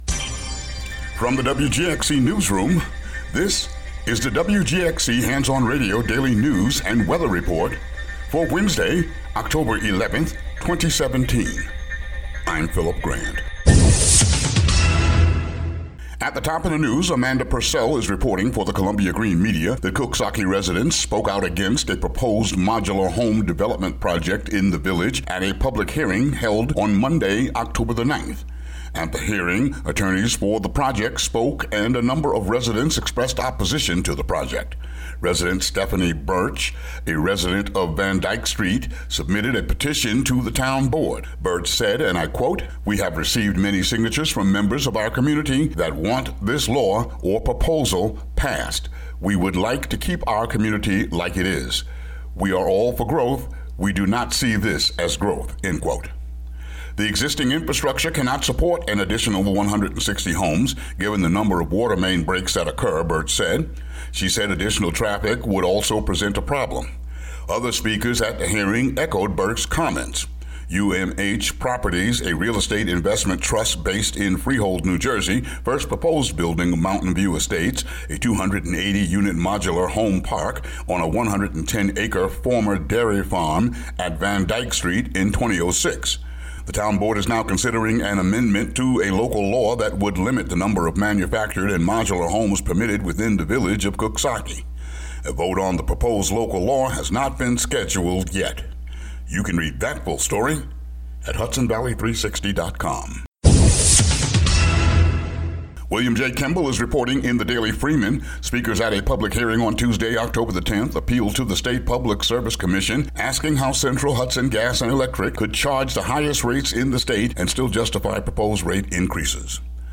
WGXC daily headlines for Oct. 11, 2017.